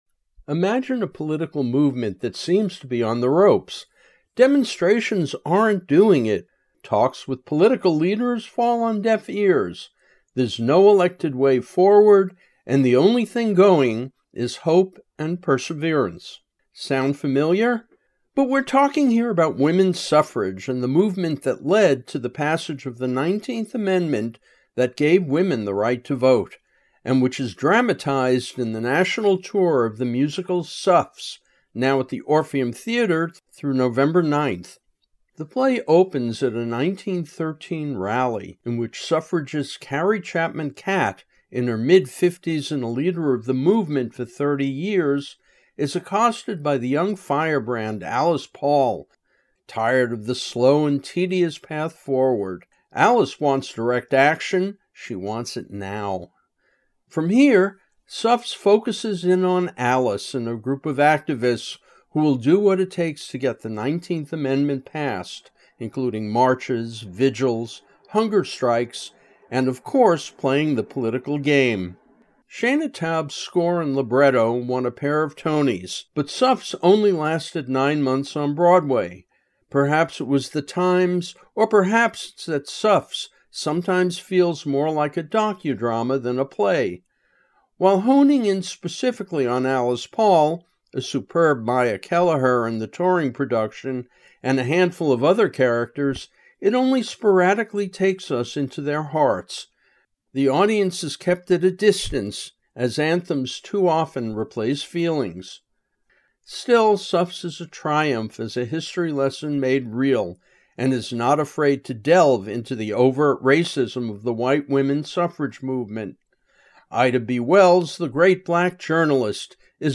Review: “Suffs” at the BroadwaySF Orpheum Theatre 2025-10-29